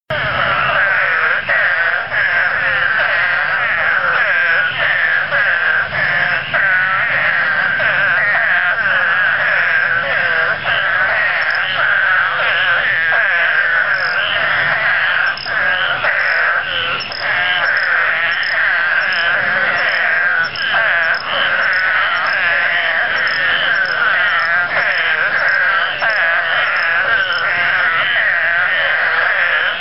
This is a chorus of Scaphiopus holbrookii, with a distant H. squirella or two, and a single Limnaoedus ocularis.